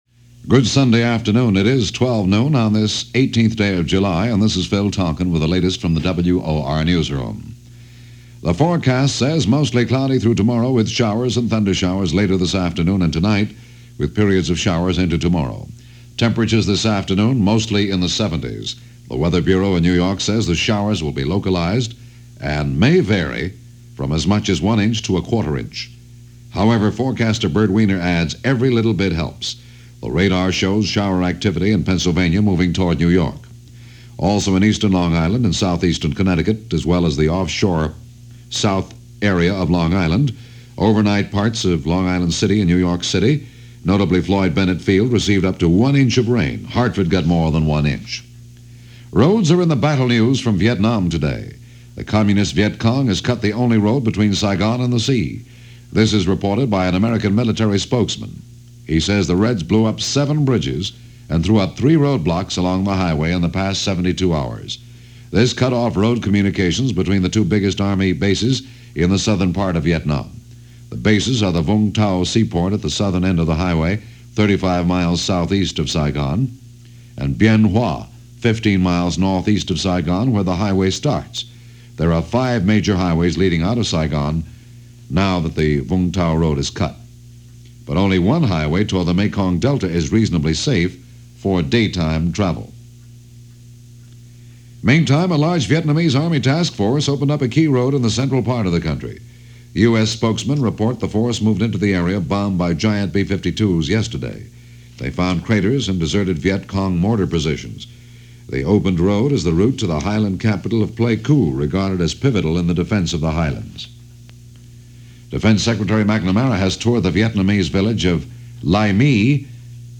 If It's July 18, 1965 - It Must Be Vietnam - news from WOR-AM, New York - Sunday, July 18, 1965 - local and national/international news.